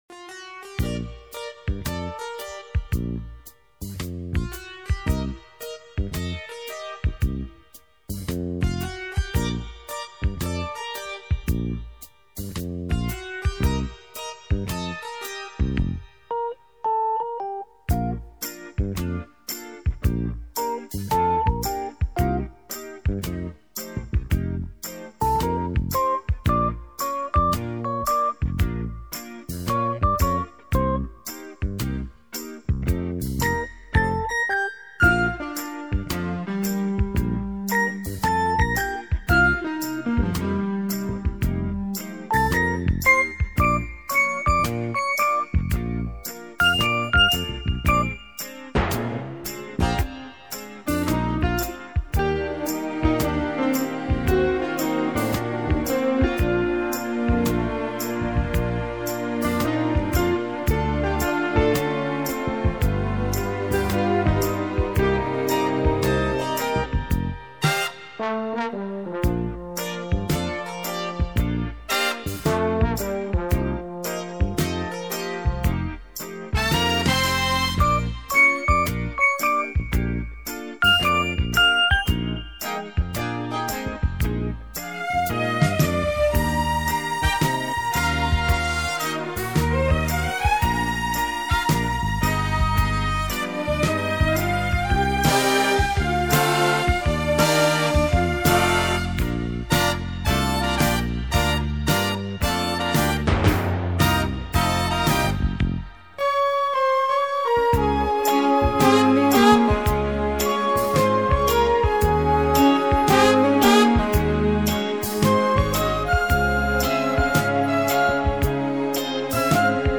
法国通俗乐团